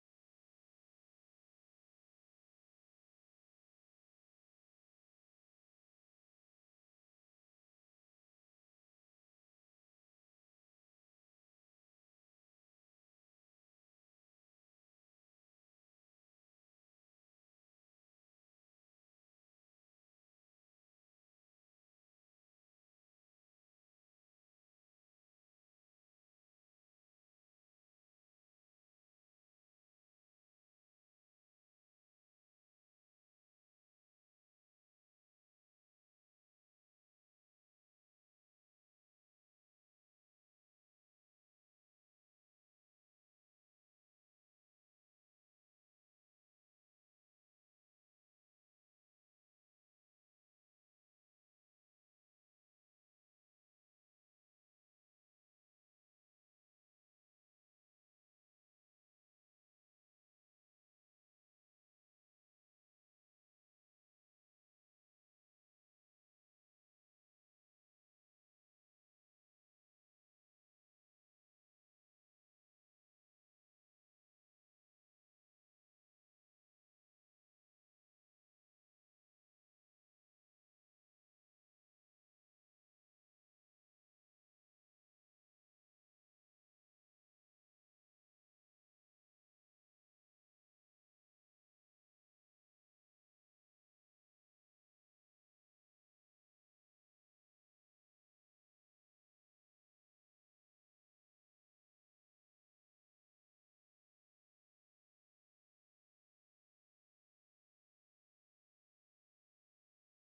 ライブ・アット・ジャズジャンボリー・フェスティバル、ワルシャワ、ポーランド 10/28/1989
サウンドボード音源で収録！！
※試聴用に実際より音質を落としています。